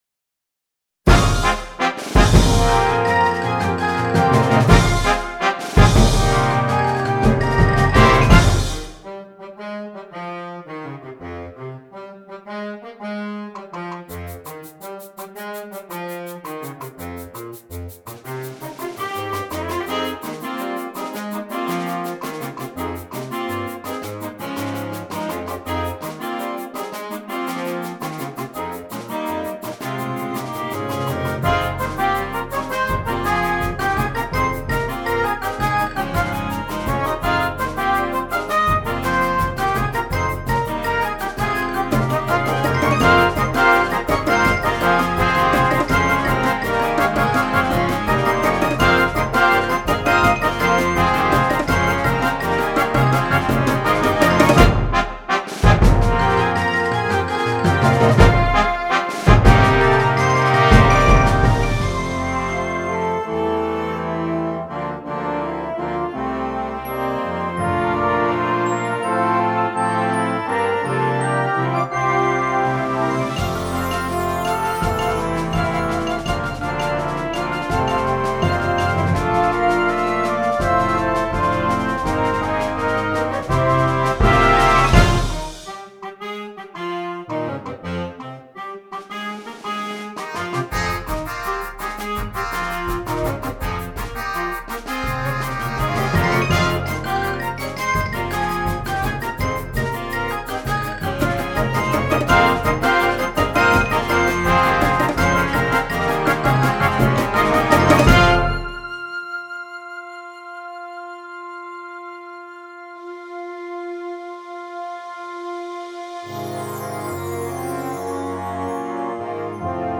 Concert Band
wind ensemble